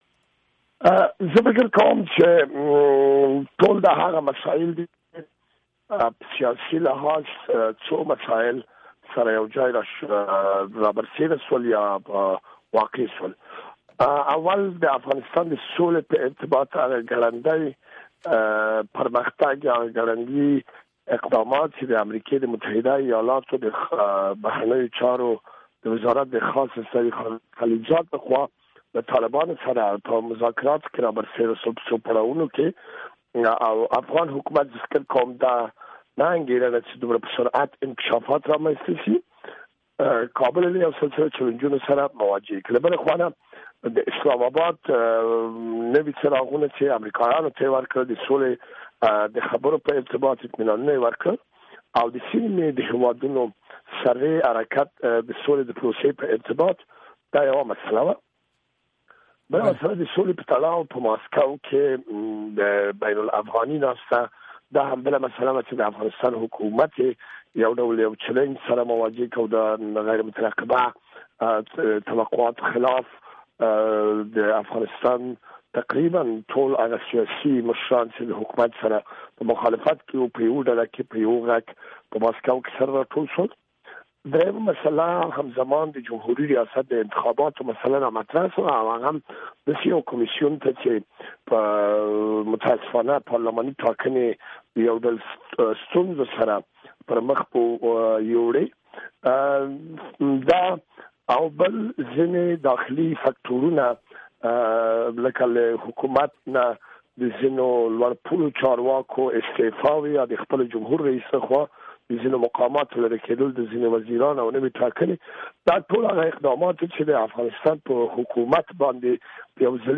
مرکه کړې ده